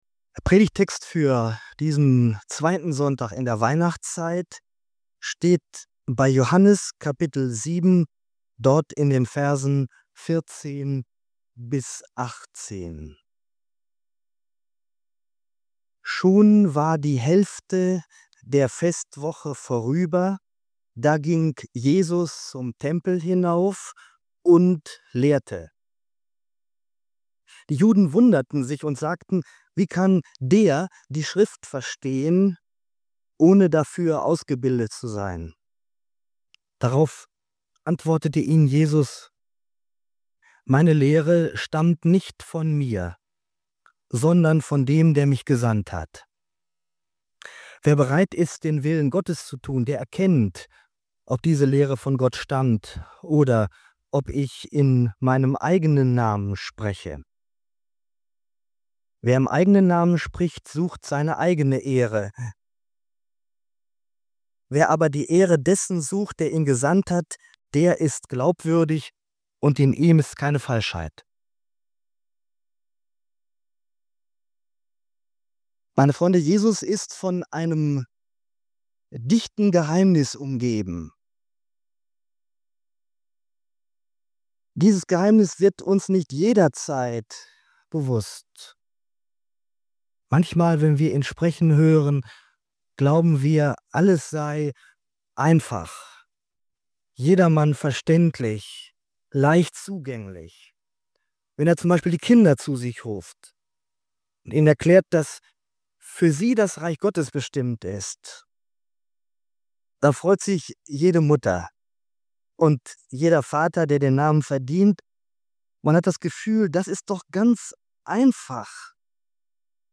Diese Predigt setzt sich mit dem Geheimnis der Autorität Jesu auseinander.